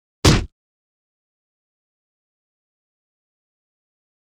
赤手空拳击中肉体-低频2-YS070524.wav
通用动作/01人物/03武术动作类/空拳打斗/赤手空拳击中肉体-低频2-YS070524.wav
• 声道 立體聲 (2ch)